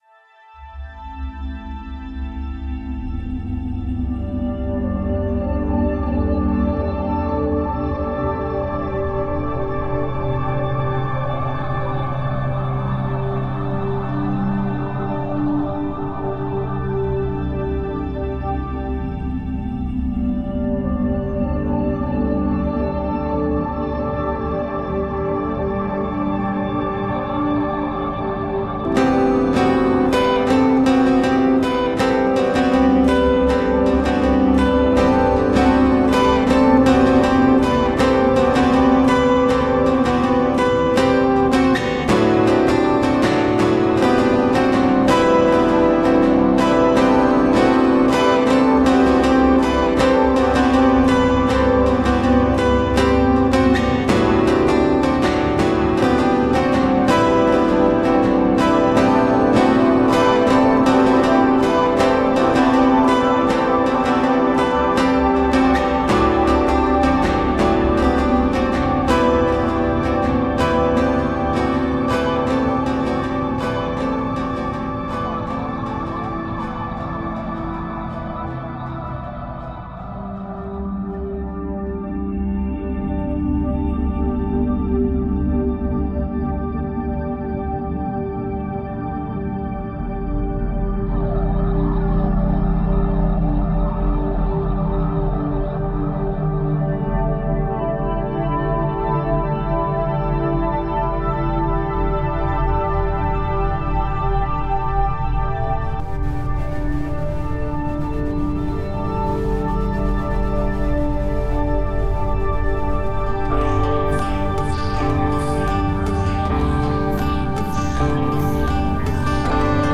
Ambient Trance Prog